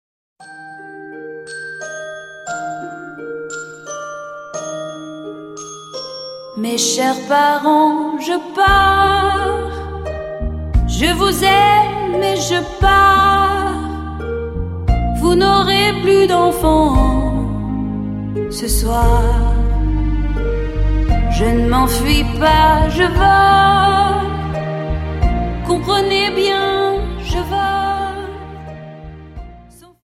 Dance: Waltz